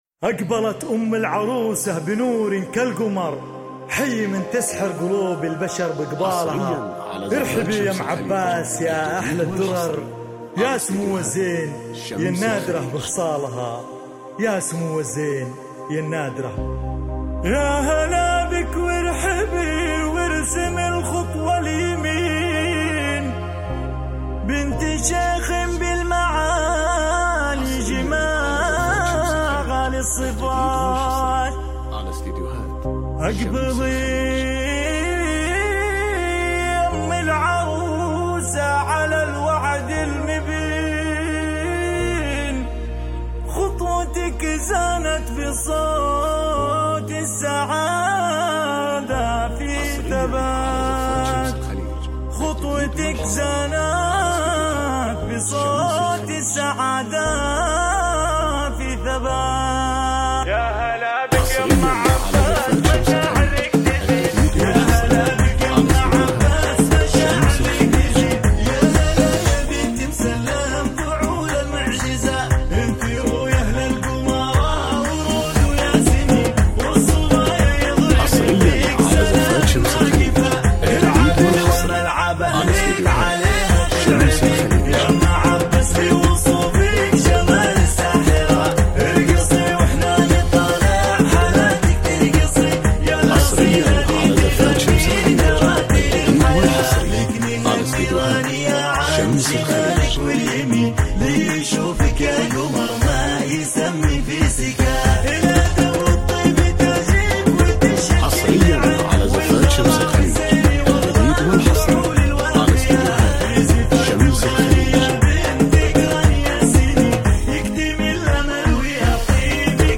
زفات موسيقى بدون اسماء